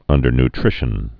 (ŭndər-n-trĭshən, -ny-)